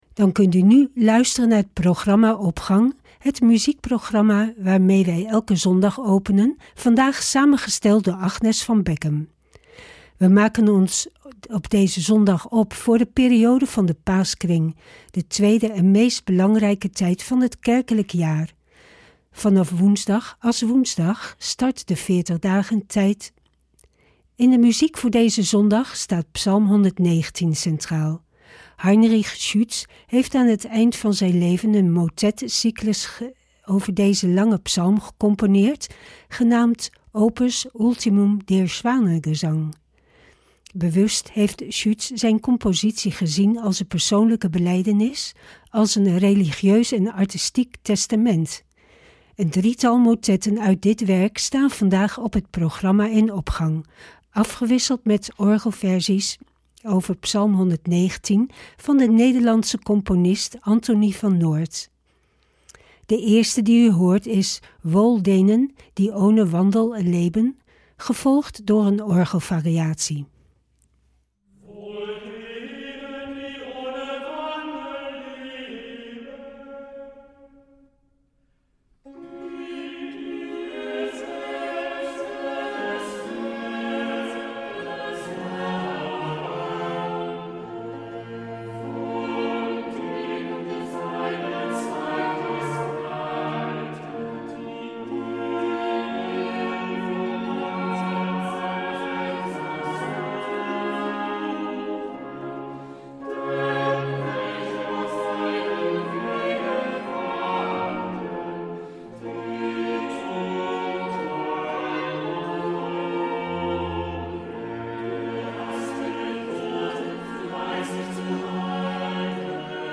Opening van deze zondag met muziek, rechtstreeks vanuit onze studio.
Anthoni van Noordt (1619 -1675) psalm 119 vers 3 (orgelvariatie); 3.
Camille Saint-Saëns (1835 – 1921) Aquarium uit Le carnaval des animaux (instrumentaal); 7.